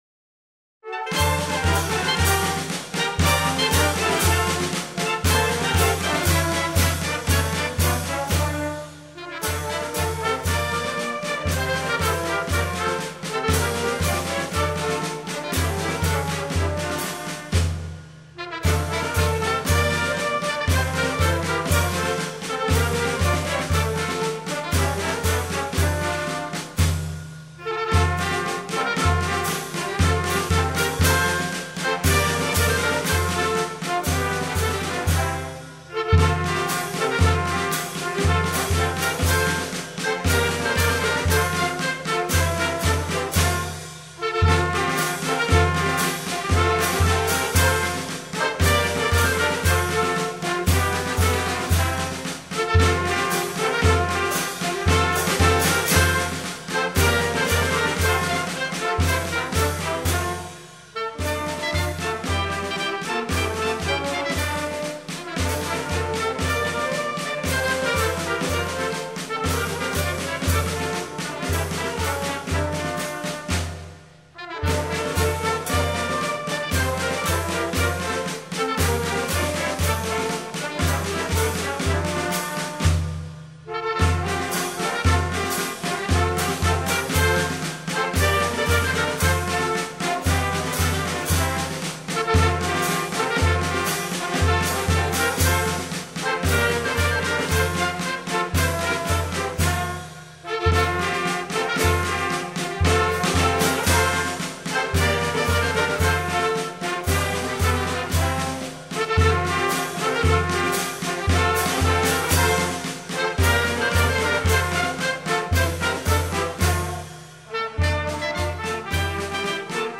Versión para banda